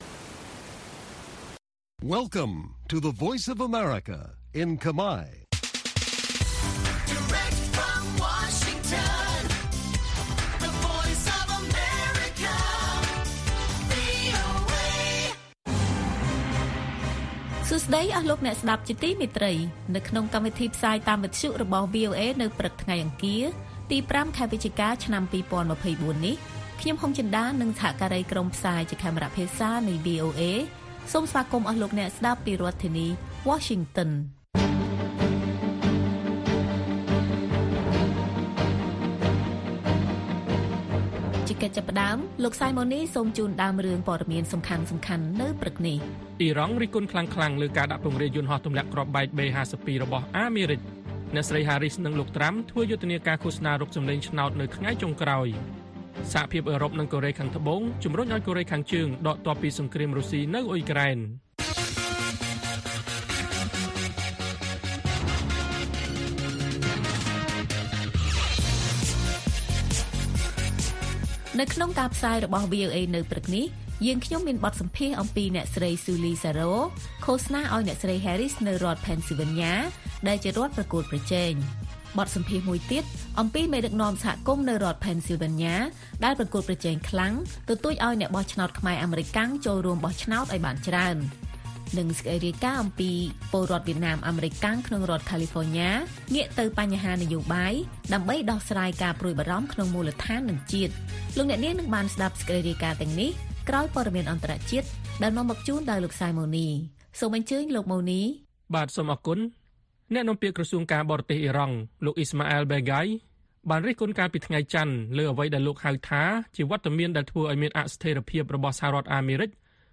បទសម្ភាសន៍អំពីមេដឹកនាំសហគមន៍នៅរដ្ឋ Pennsylvania ដែលប្រកួតប្រជែងខ្លាំងទទូចឲ្យអ្នកបោះឆ្នោតខ្មែរអាមេរិកាំងចូលរួមបោះឆ្នោតឲ្យបានច្រើន និងព័ត៌មានផ្សេងៗទៀត៕